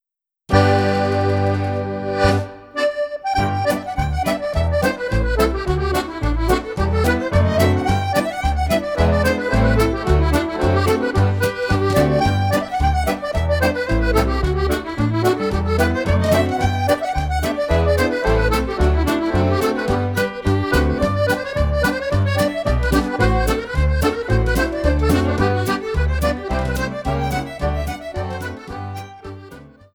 Reel